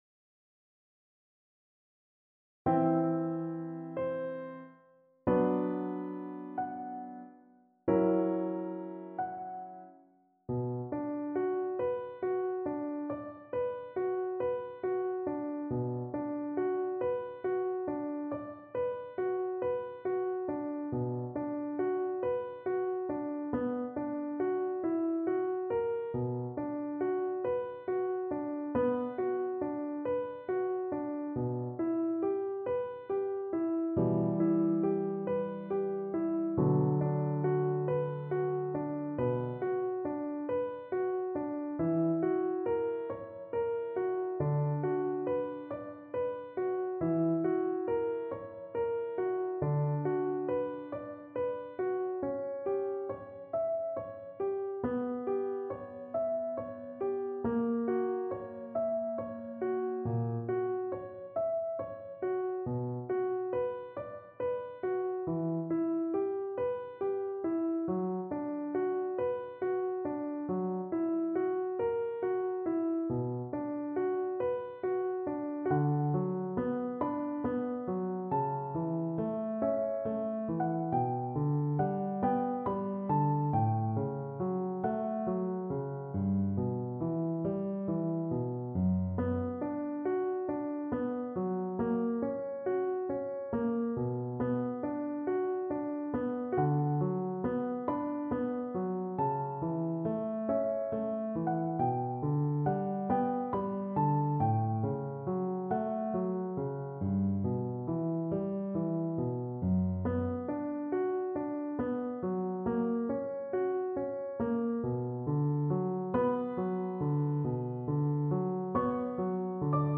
Cello
D major (Sounding Pitch) (View more D major Music for Cello )
Andante cantabile =46
4/4 (View more 4/4 Music)
Classical (View more Classical Cello Music)